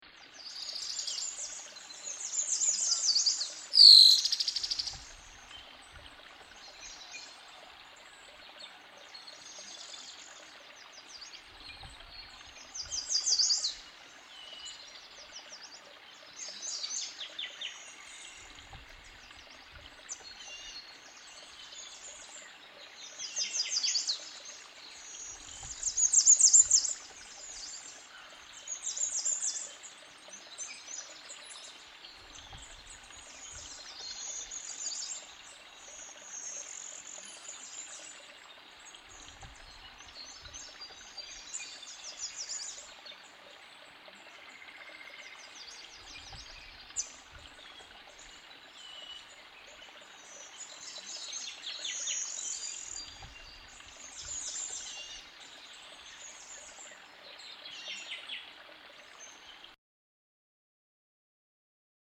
Birds-and-water.mp3